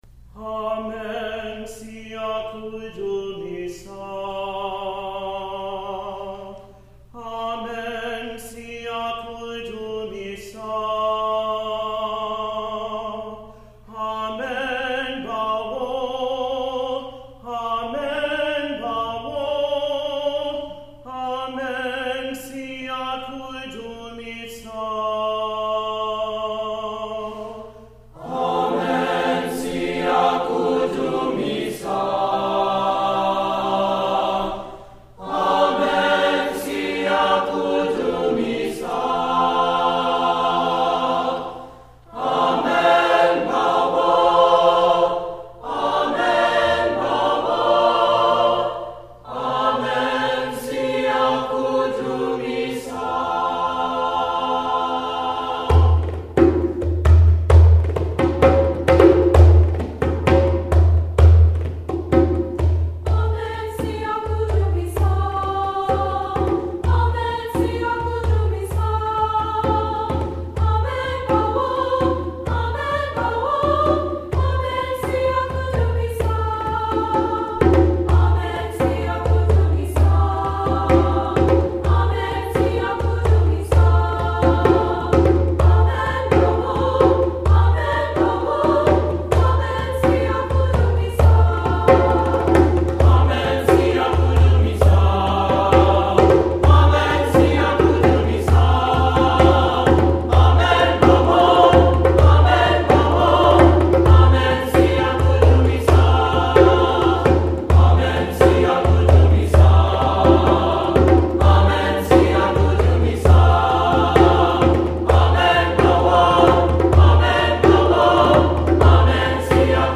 Composer: South African Spiritual
Voicing: 3-Part Treble